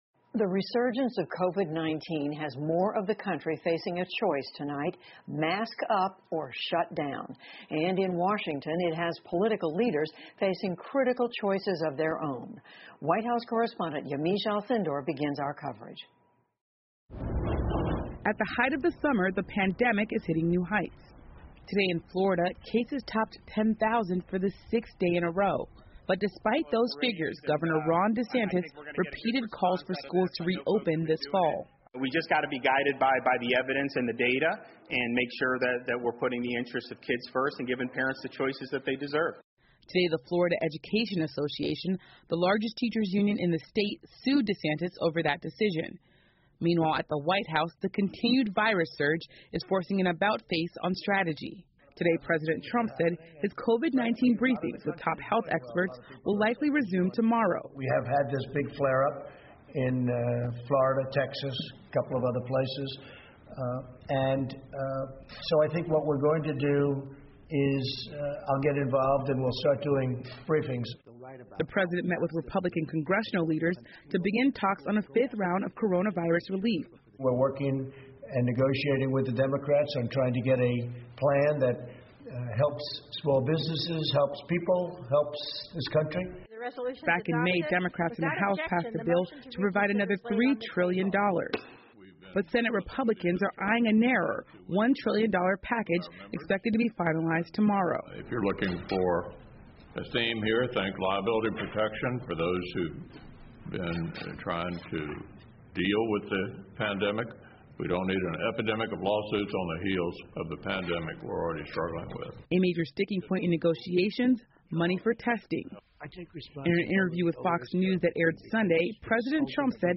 PBS高端访谈:特朗普恢复新冠疫情发布会 听力文件下载—在线英语听力室